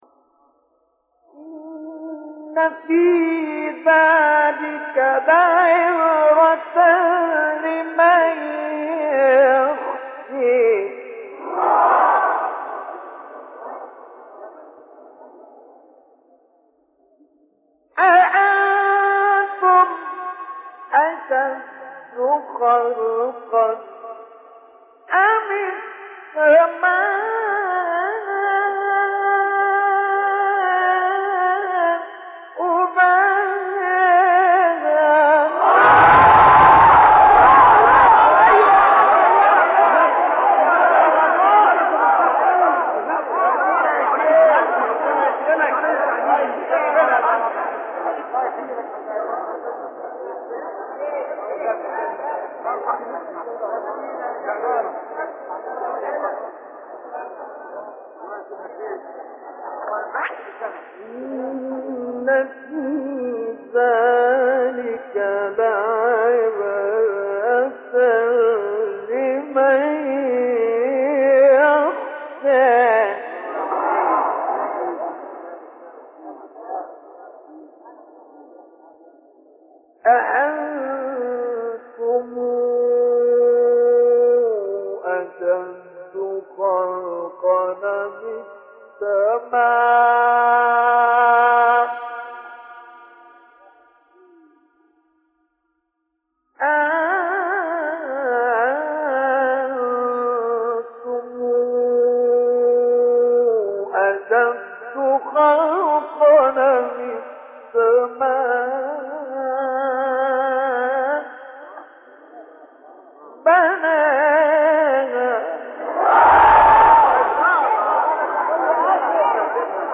آیه 26-46 سوره نازعات استاد مصطفی اسماعیل | نغمات قرآن | دانلود تلاوت قرآن